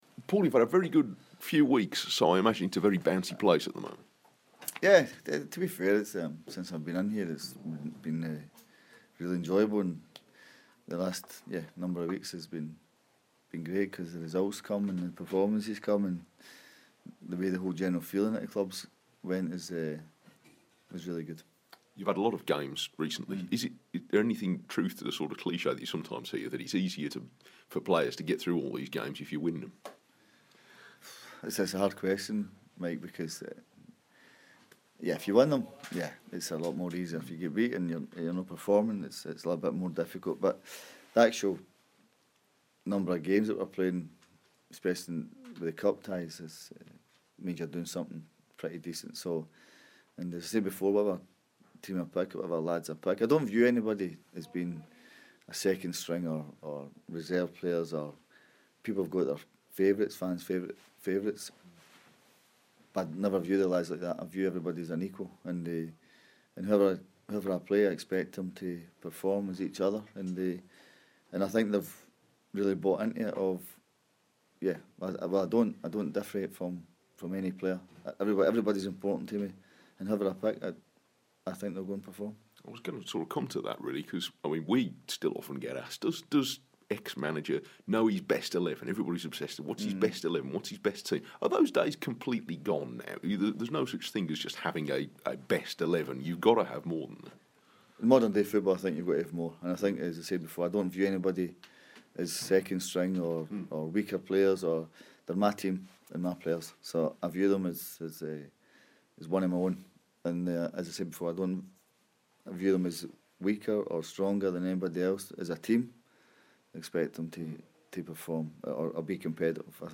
Wolves boss Paul Lambert previews his teams trip to Burton after making 6 changes in the midweek win at Barnsley.